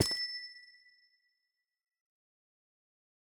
Minecraft Version Minecraft Version 1.21.5 Latest Release | Latest Snapshot 1.21.5 / assets / minecraft / sounds / block / amethyst / step5.ogg Compare With Compare With Latest Release | Latest Snapshot
step5.ogg